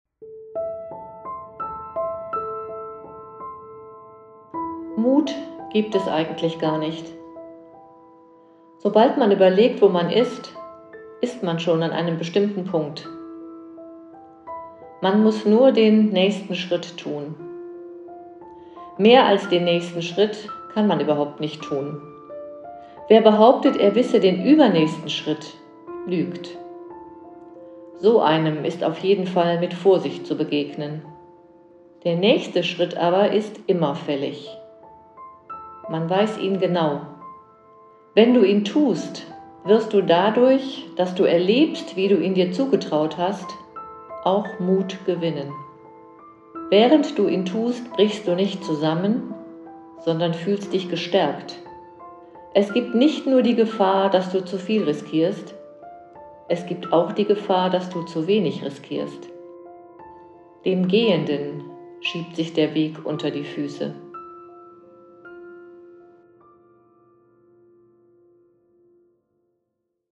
Gesprochene Texte zum Thema Trauer